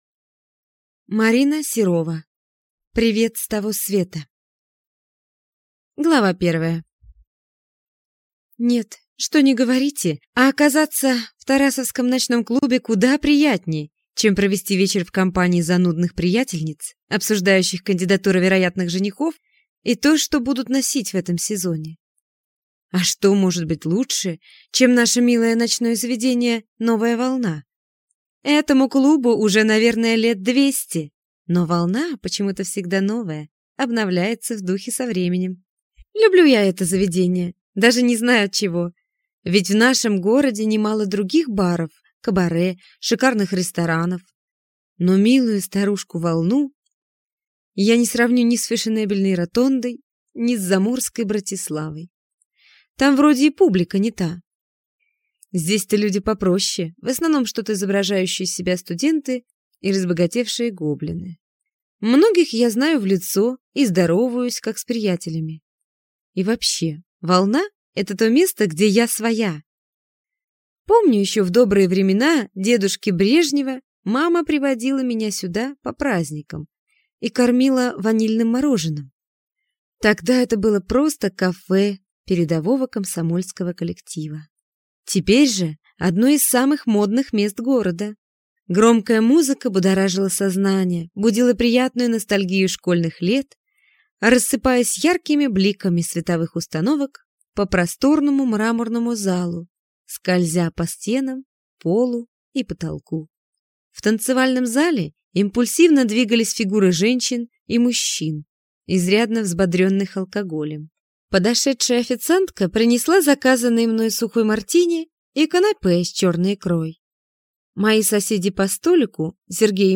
Аудиокнига Привет с того света | Библиотека аудиокниг